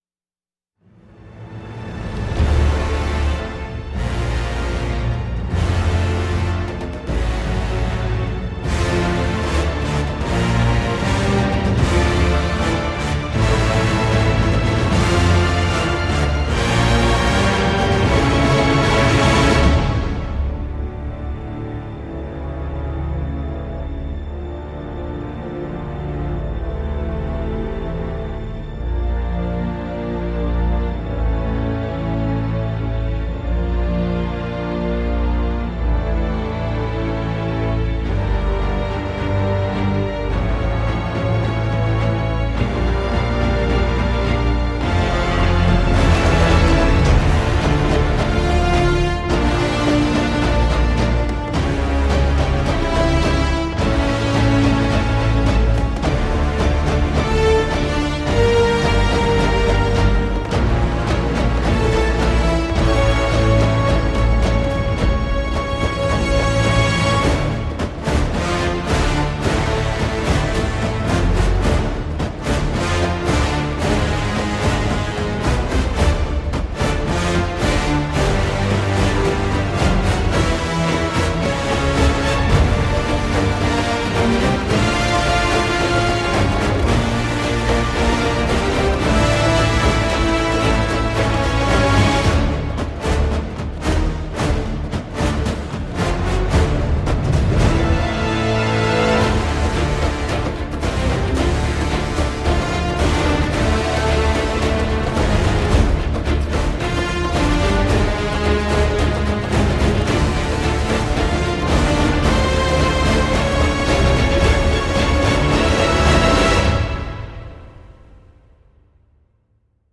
Ça tourne vite en rond et c’est super long.